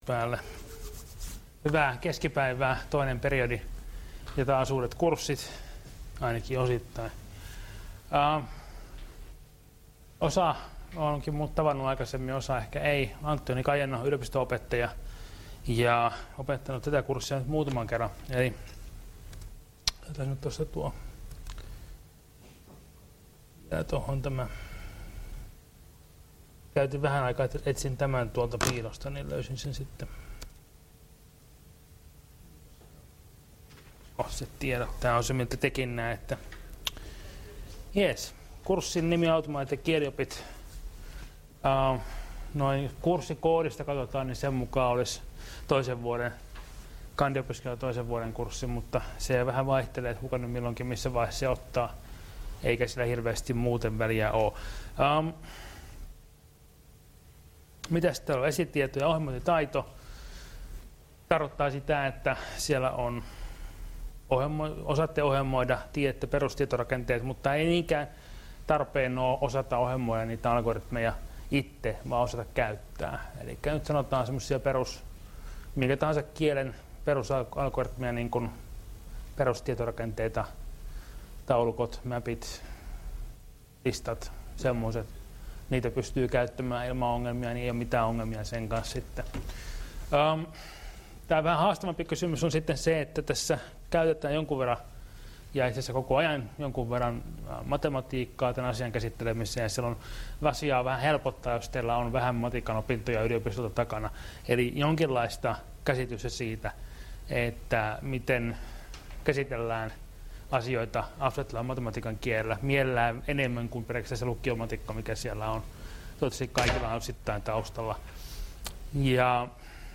Luento 23.10.2017 f9ce0ddb6fce4b9bae5a159c25d12faa